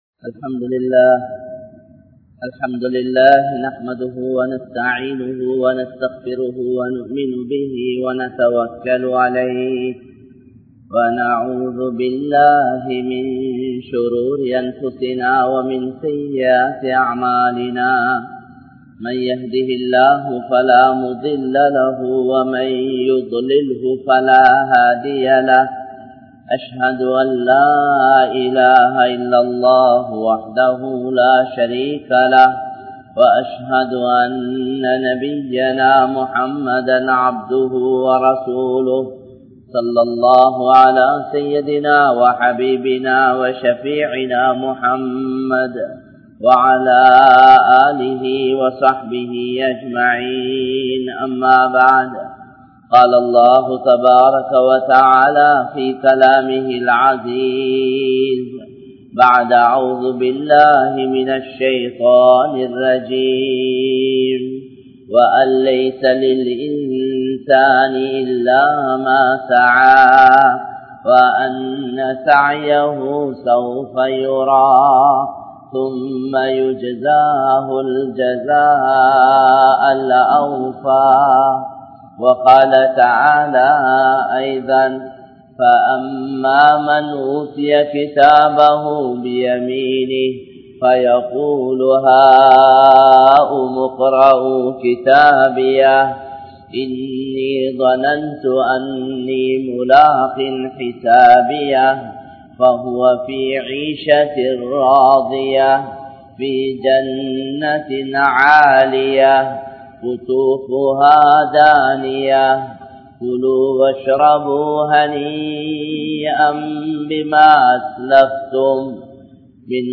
Allahvukaaha Vaalvoam | Audio Bayans | All Ceylon Muslim Youth Community | Addalaichenai